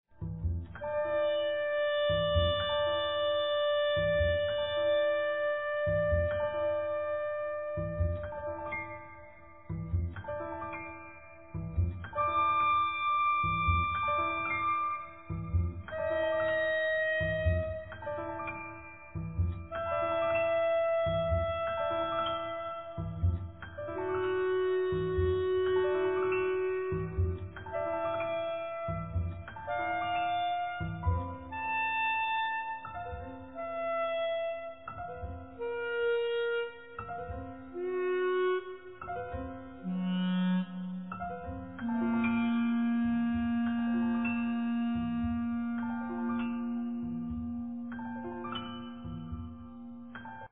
Clarinet
Bass
Sax
Trumpet
Drums, Percussions